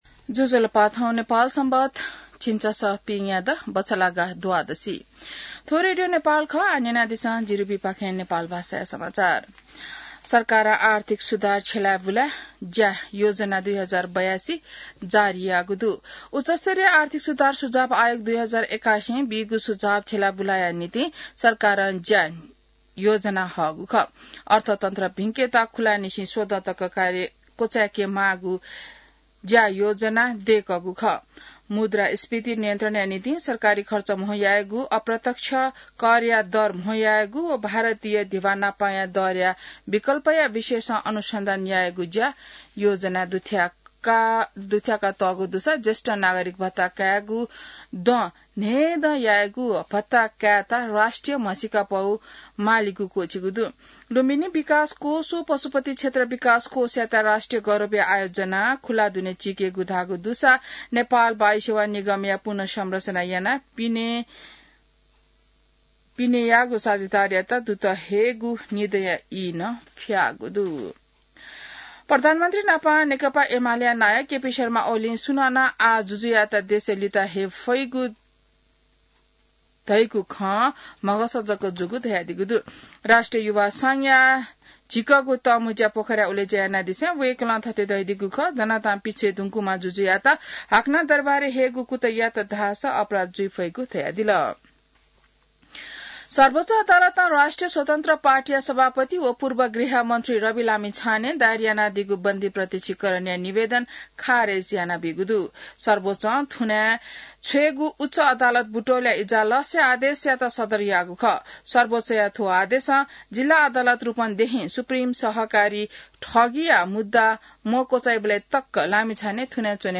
नेपाल भाषामा समाचार : १० जेठ , २०८२